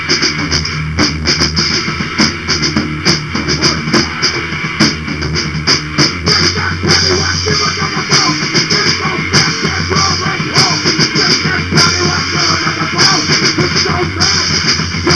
Korn Style "Nick Nack Paddy Wack, Give a dog a bone"